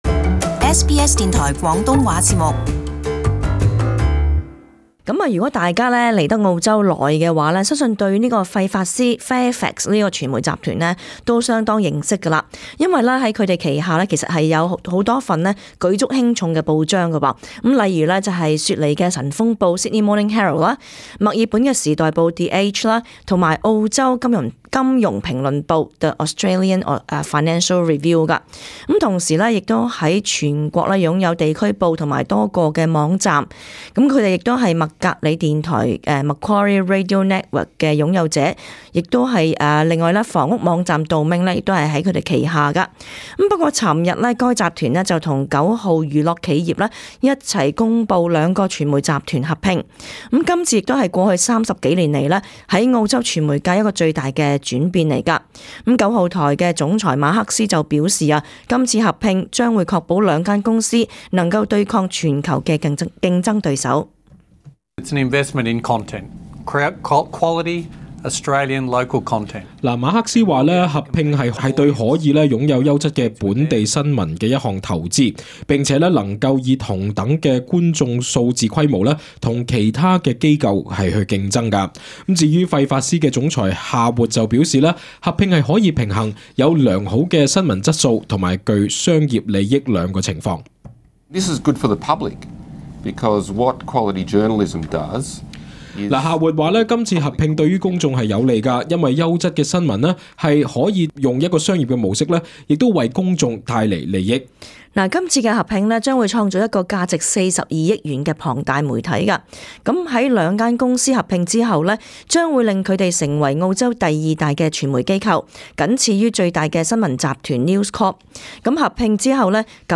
【時事報導】:九號娛樂企業及費法斯傳媒集團宣布合併